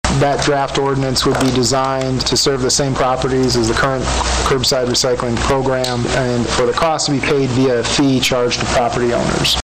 Sturgis City Manager Andrew Kuk gives an overview of what the ordinance would do for Sturgis.